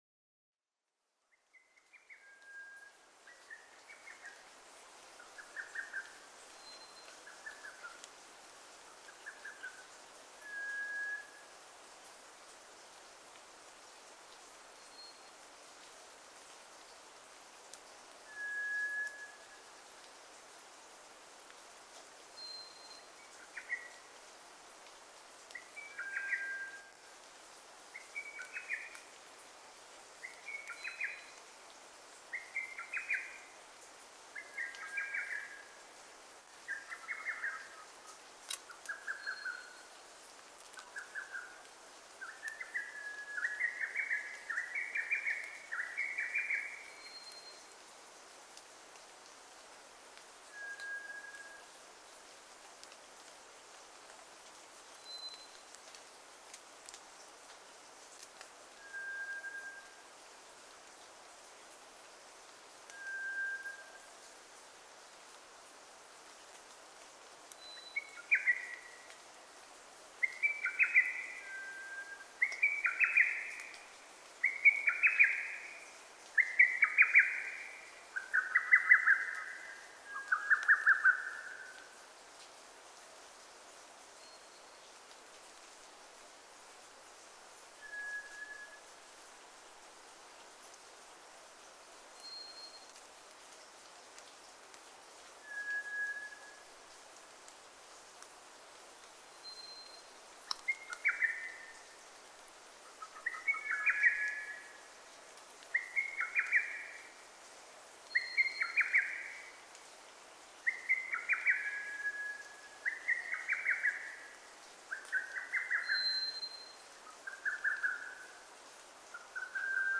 トラツグミ　Zoothera daumaツグミ科
日光市稲荷川上流　alt=1330m
Mic: built-in Mic.
深夜の森で、虎ツグミとホトトギスが鳴き続けます。
他の自然音：ホトトギス